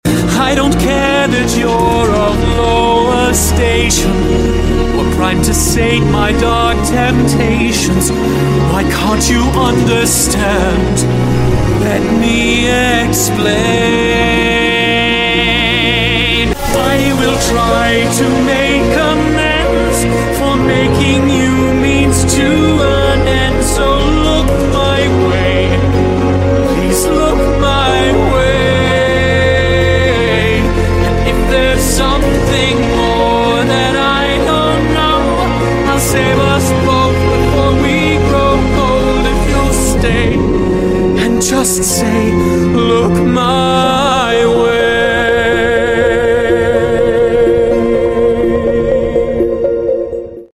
HIS VOICE IS PERFECT FOR STOLAS.